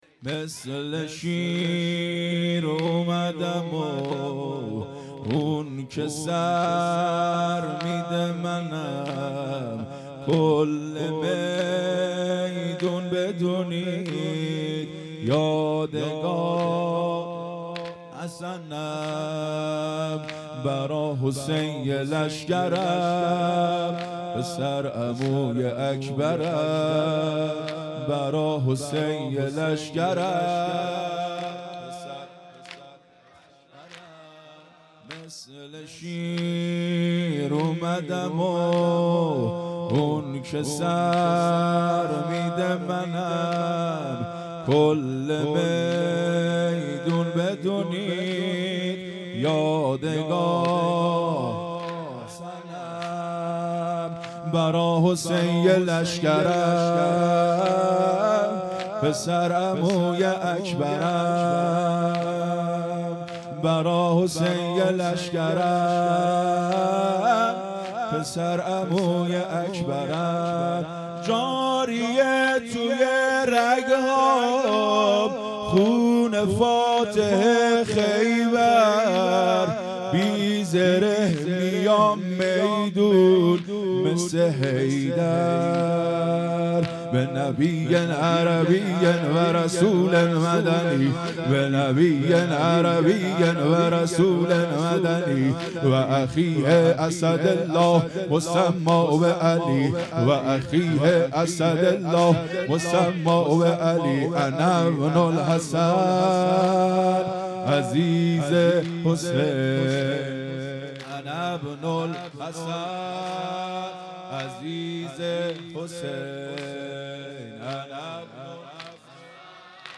زمینه|مثل شیر امدم
هیئت مکتب الزهرا(س)دارالعباده یزد
محرم ۱۴۴۵_شب ششم